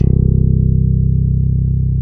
Index of /90_sSampleCDs/Roland - Rhythm Section/BS _Rock Bass/BS _Chapmn Stick